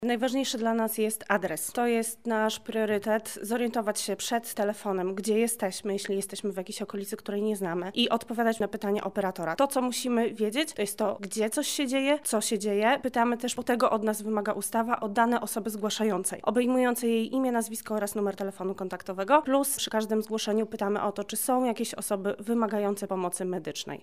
O tym, jakich informacji udzielać, aby służby ratunkowe jak najszybciej dotarły na miejsce zdarzenia, mówi jedna z operatorek numerów alarmowych:
Operatorka numeru 112